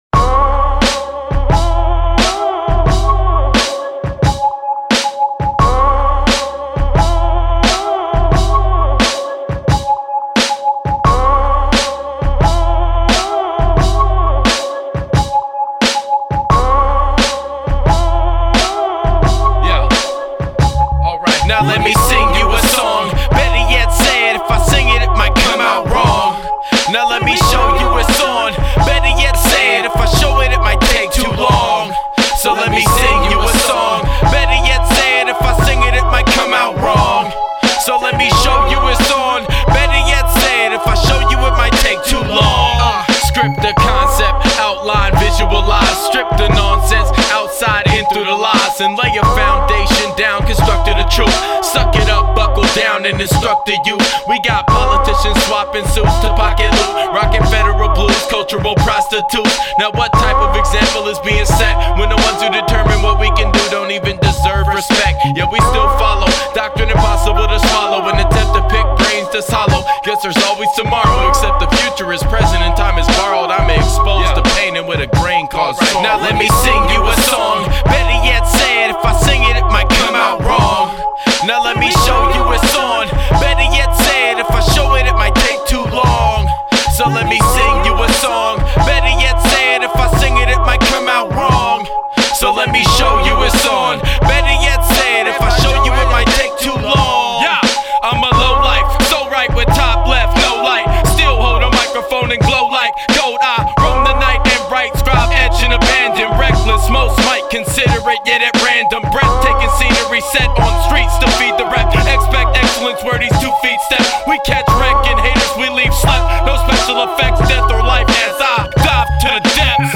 Recorded at Ground Zero Studios & Seattle ChopShop